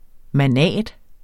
Udtale [ maˈnæˀd ]